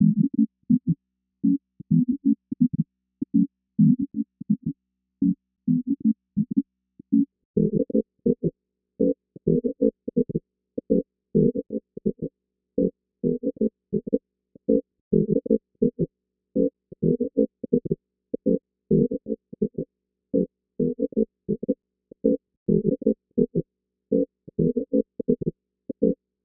nord sourd 02 reprise resonance.wav